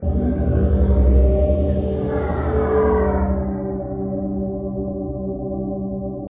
描述：Created in Padshop
标签： Ambient Stuff Soundscape
声道立体声